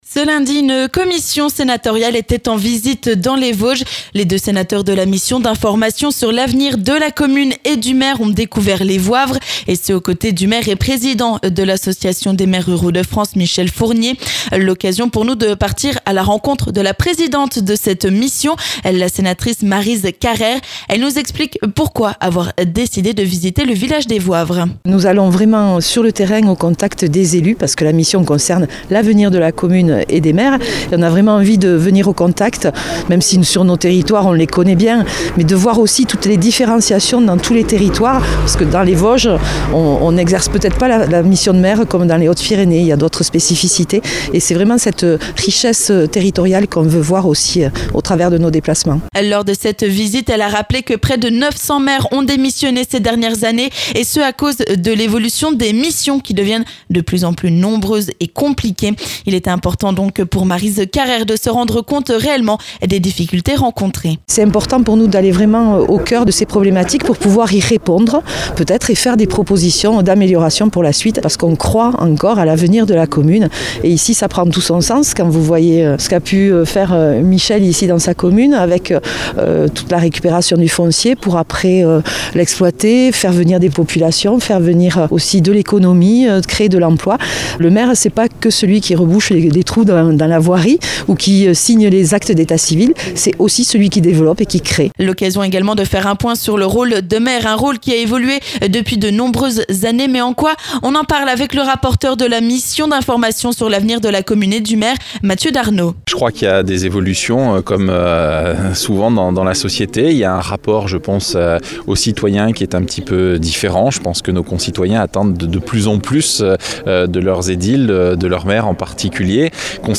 On en parle avec la présidente de cette mission, Maryse Carrère, son rapporteur, Mathieu Darnaud et le maire de Le Voivres et président de l'association des maires ruraux de France, Michel Fournier.